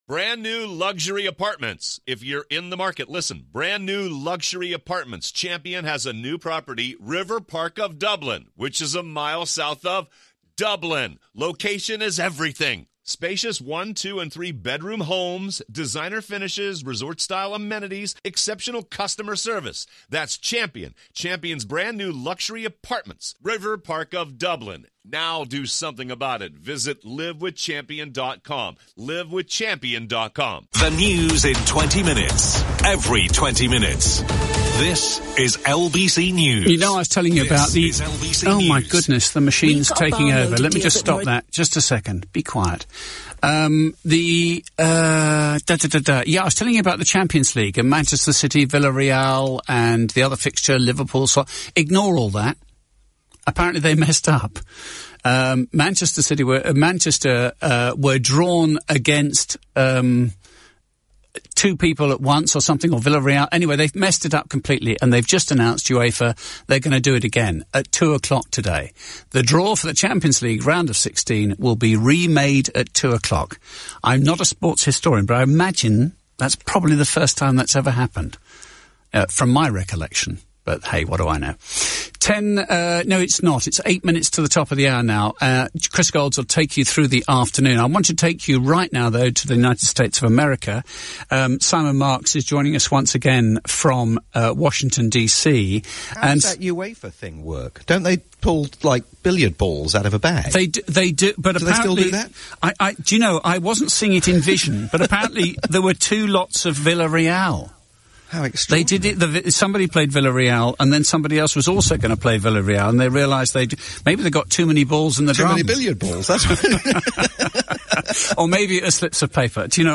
live roundup for LBC News